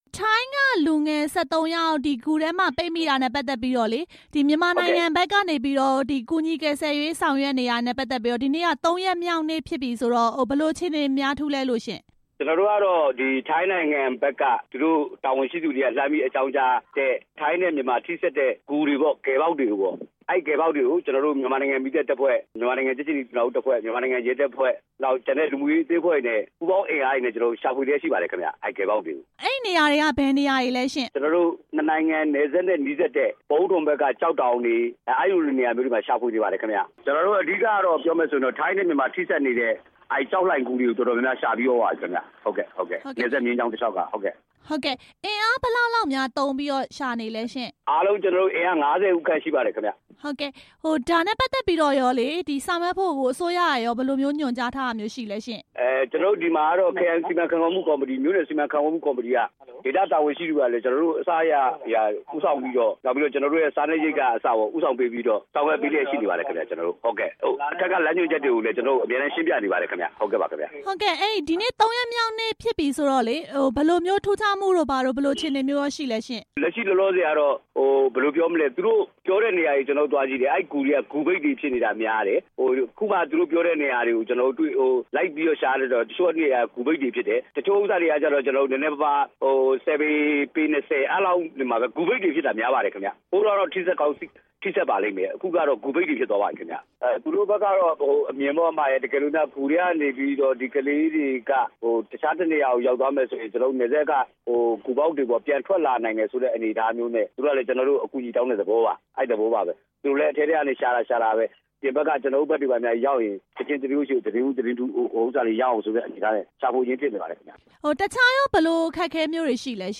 ပျောက်ဆုံး ၁၃ ဦး မြန်မာဘက်ခြမ်းရှာဖွေမှုအကြောင်း မေးမြန်းချက်